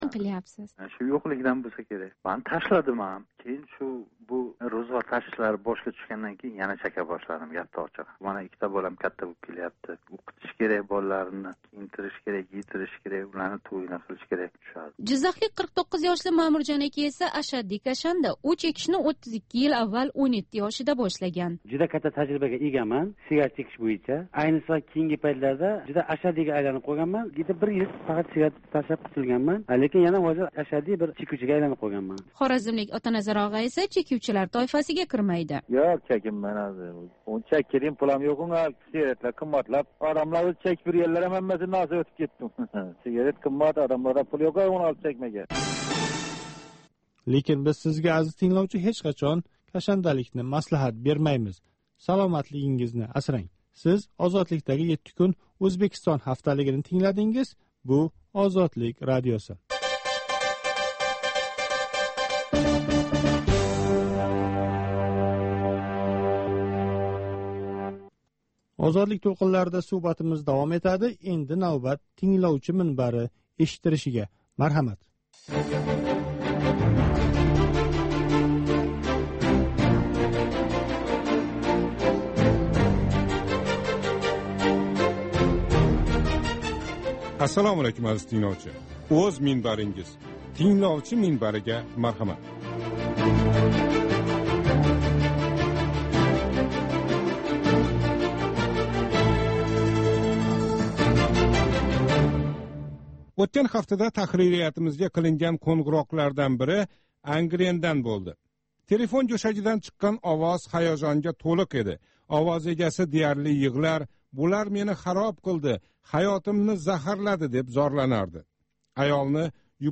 "7 кун - Ўзбекистон": Ҳафта давомида Ўзбекистон сиëсий¸ иқтисодий-ижтимоий ҳаëти¸ қолаверса мамлакатдаги инсон ҳуқуқлари ва демократия вазияти билан боғлиқ долзарб воқеалардан бехабар қолган бўлсангиз "7 кун - Ўзбекистон" ҳафталик радиожурналимизни тинглаб боринг. Бу туркум ҳафтанинг энг муҳим воқеалари калейдоскопидир!